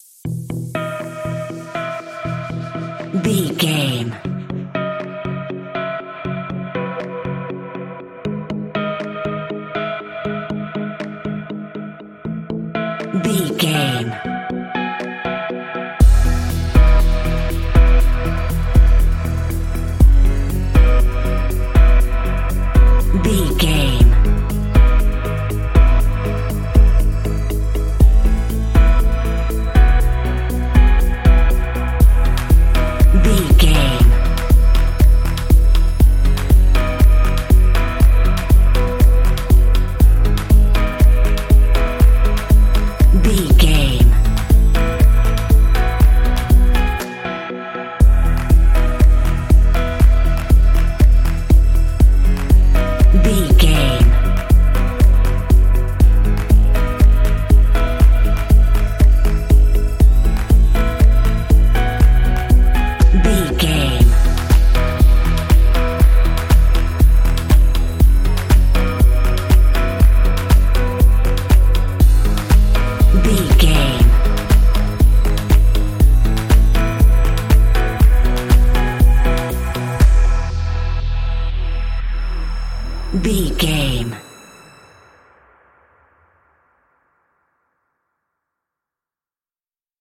Uplifting
Ionian/Major
Fast
upbeat
electronic
piano
synth
pop
bright
energetic
drum machine